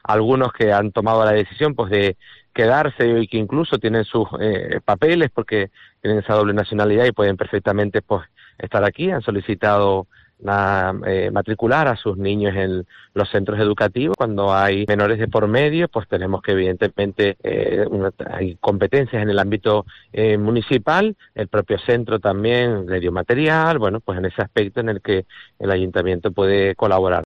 Marco González, alcalde de Puerto de la Cruz, explica la atención prestada a los turistas ucranianos